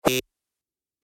دانلود آهنگ زنگ 18 از افکت صوتی اشیاء
دانلود صدای زنگ 18 از ساعد نیوز با لینک مستقیم و کیفیت بالا
جلوه های صوتی